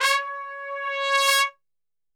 C#3 TRPSWL.wav